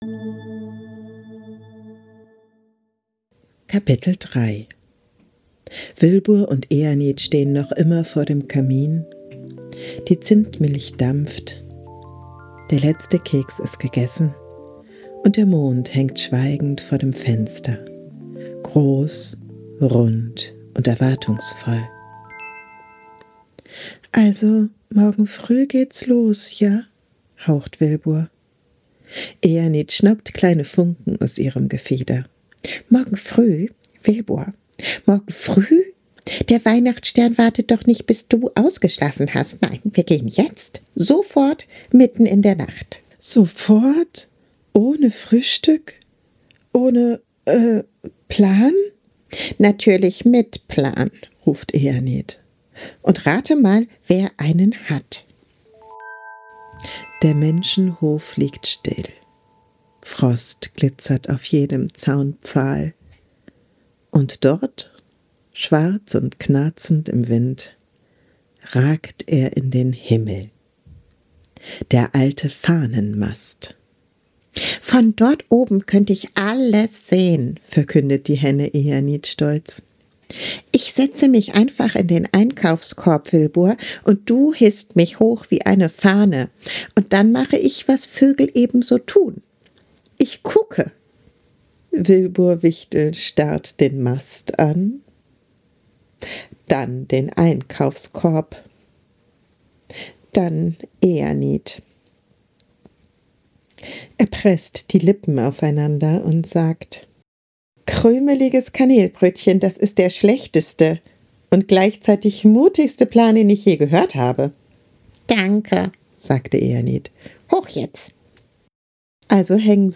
Eine ruhige Weihnachtsgeschichte für Kinder ab 4 Jahren. Wilbur, ein gemütlicher Hauswichtel, und Éanid, ein abenteuerlustiges Huhn, geraten mitten im Winter in abenteuerliche Wirrnisse.
Ein moderner Märchen-Podcast für Kinder